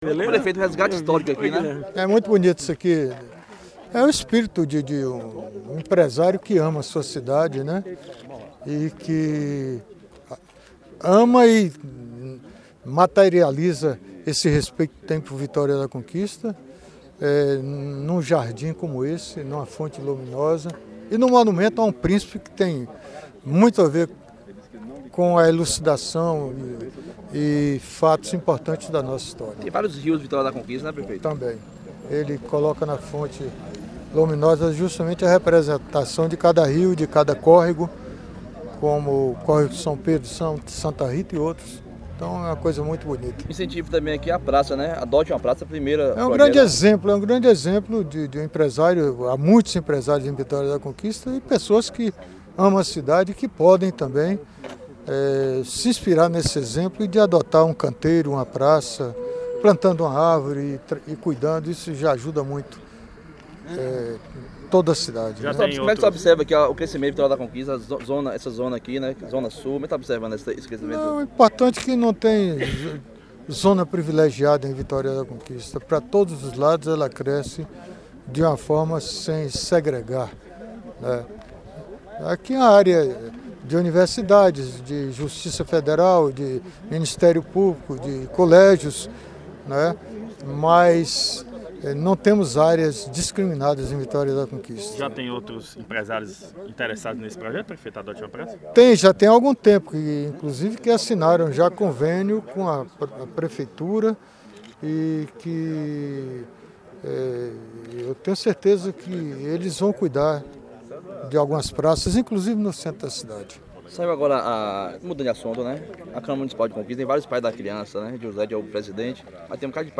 Entrevista ao UP Notícias | Zé Bahia faz balanço de gestão e destaca as decepções dos opositores em Tremedal